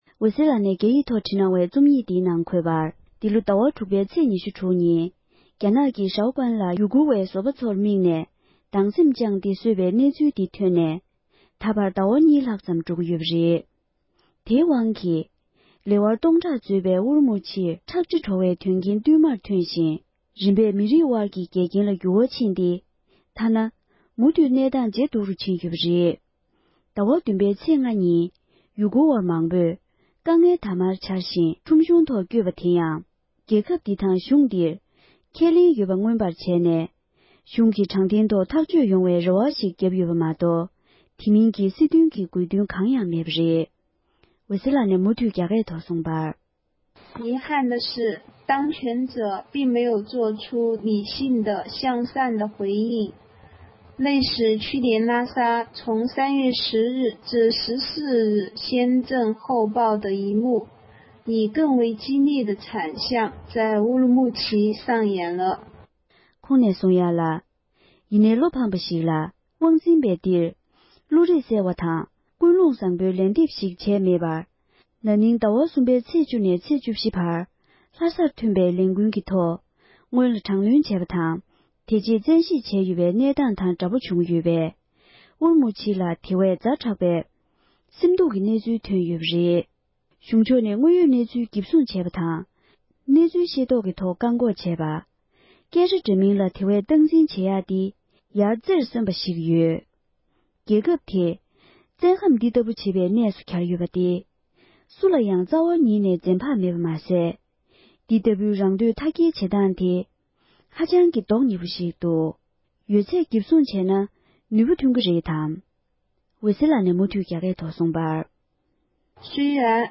བོད་སྐད་ཐོག་ཕབ་བསྒྱུར་གྱིས་སྙན་སྒྲོན་ཞུས་པར་གསན་རོགས༎